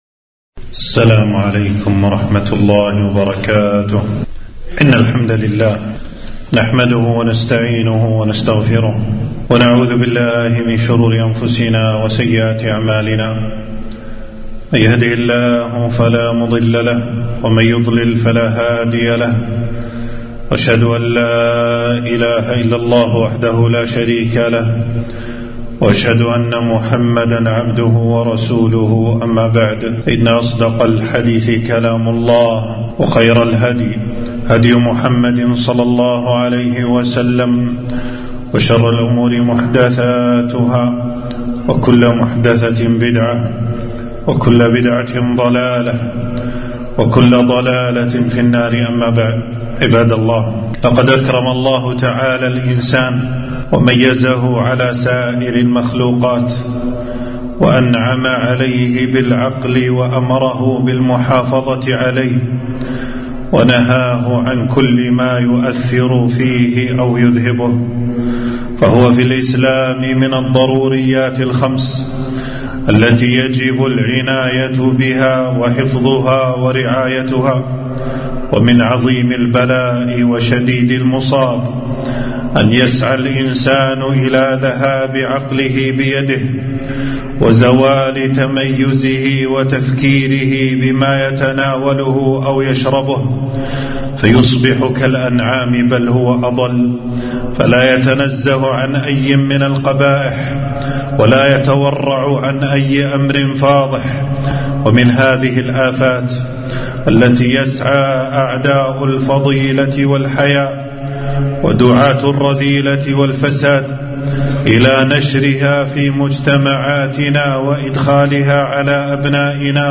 خطبة - خطورة المسكرات والمخدرات